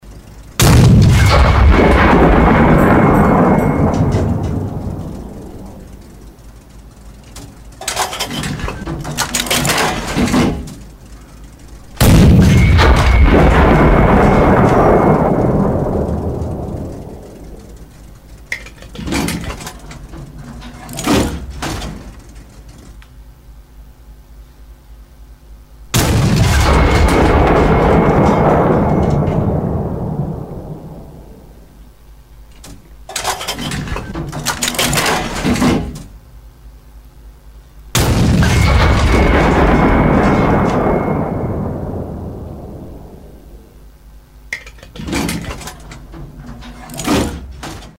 Вы можете слушать онлайн или скачать эффекты в высоком качестве: от глухих залпов тяжелых пушек до резких выстрелов легкой артиллерии.
Выстрел 105 мм орудия США